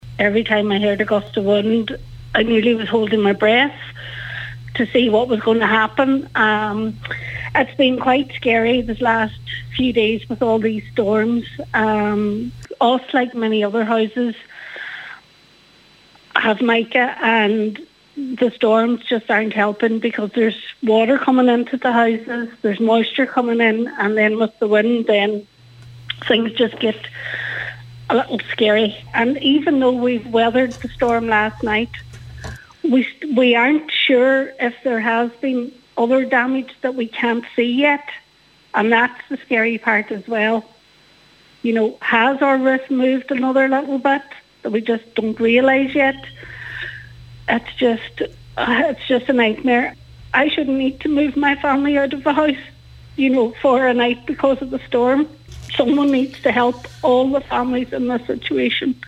spoke on today’s Nine Till Noon Show